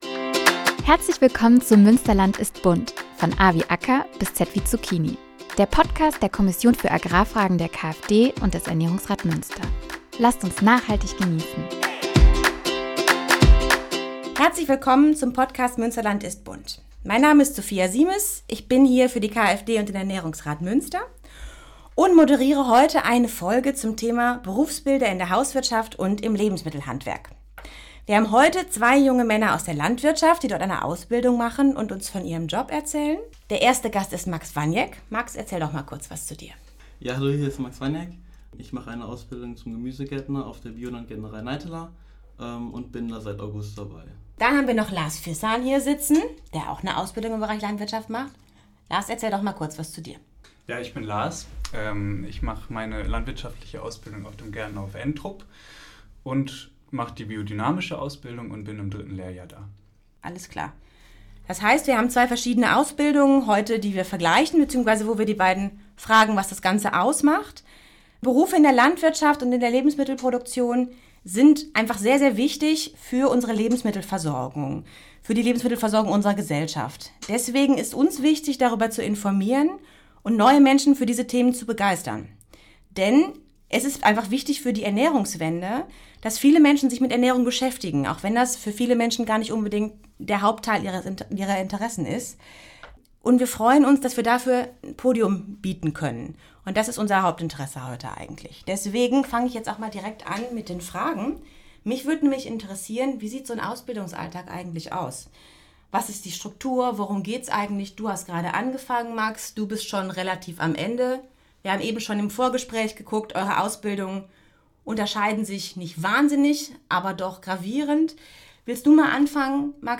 Warum entscheiden sich junge Menschen heute für eine Ausbildung in der Landwirtschaft – und dann auch noch im biodynamischen Bereich? In dieser Folge sprechen wir mit zwei Azubis, die mitten in ihrer landwirtschaftlichen Ausbildung stecken.